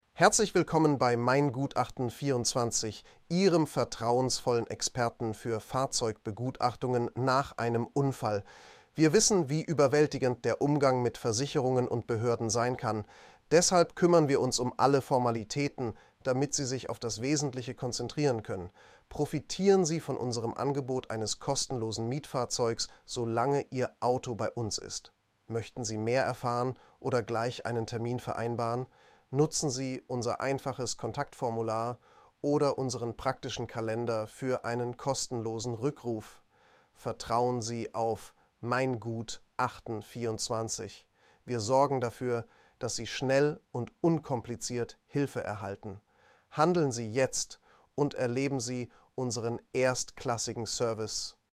texttospeech.mp3